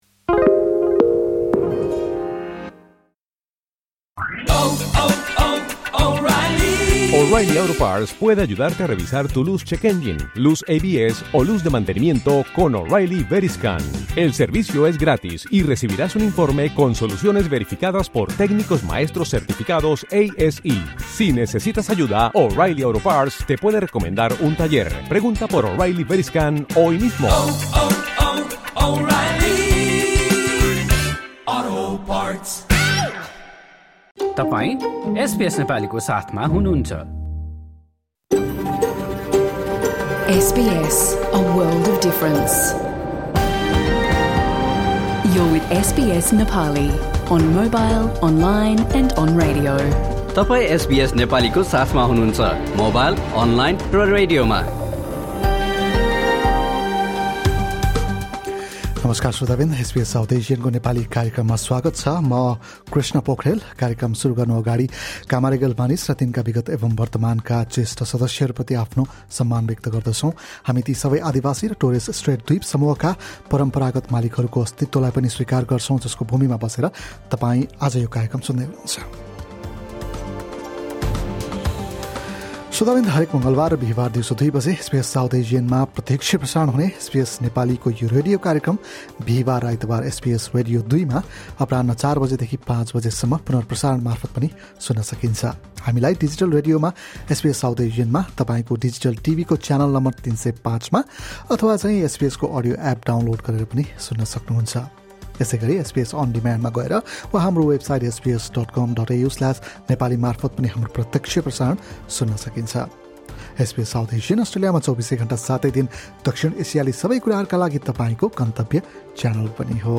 Radio program: Australian PM’s marriage, diabetes care tips and news from Nepal | SBS Nepali